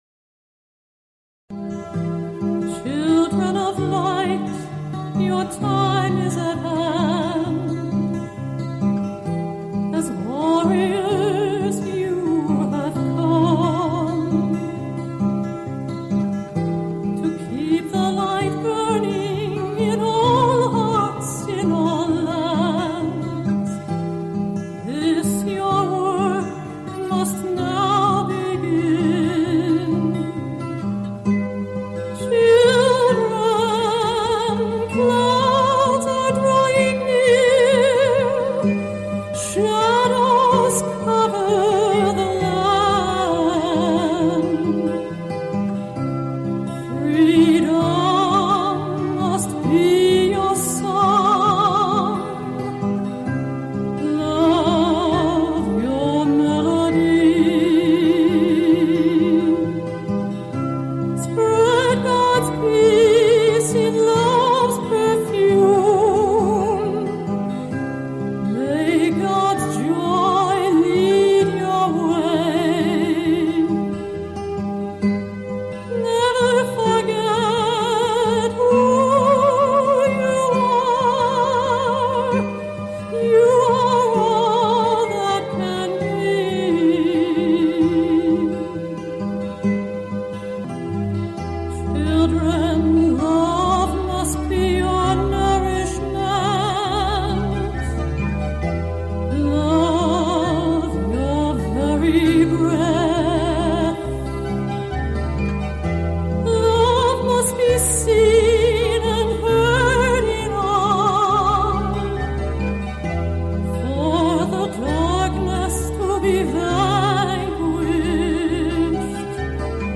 This is a call in show